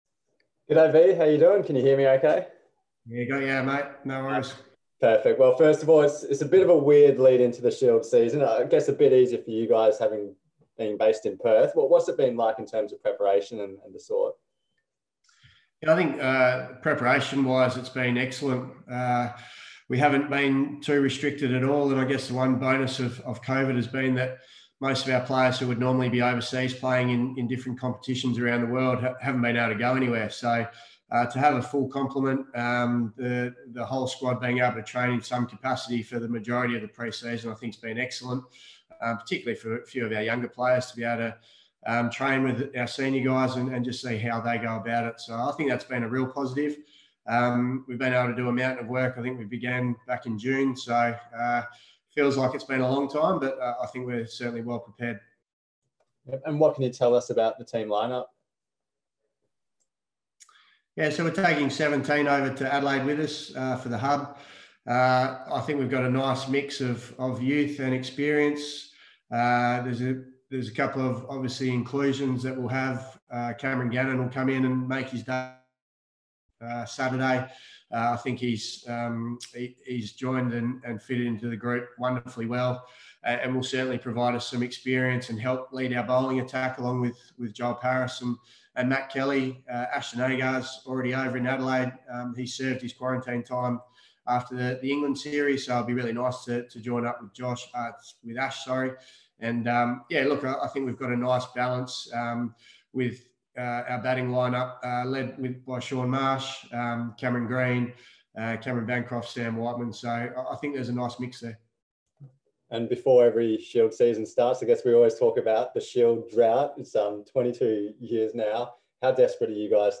The Western Australia Men’s coach spoke to media today ahead of the squad’s departure to Adelaide to begin their 2020-21 Marsh Sheffield Shield season.